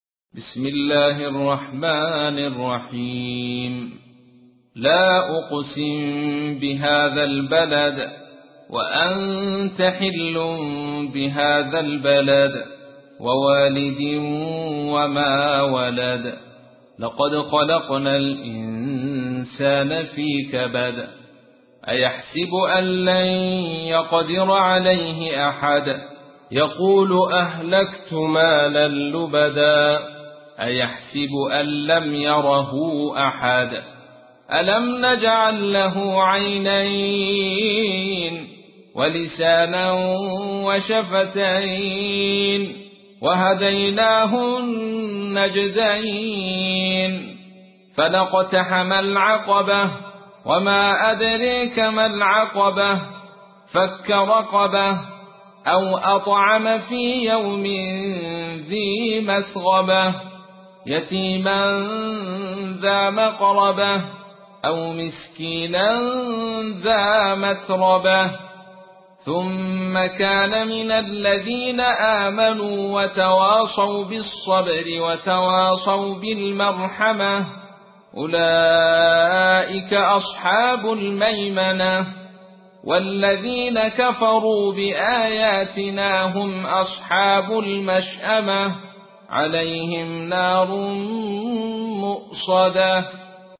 تحميل : 90. سورة البلد / القارئ عبد الرشيد صوفي / القرآن الكريم / موقع يا حسين